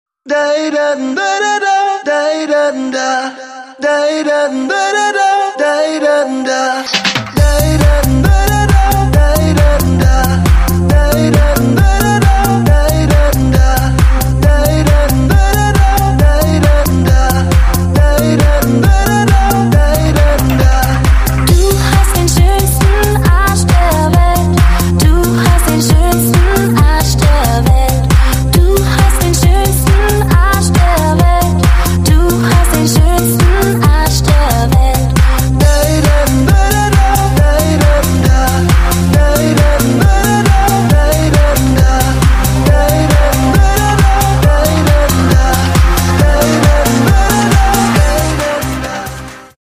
DJ铃声